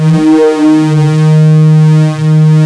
OSCAR 10 D#3.wav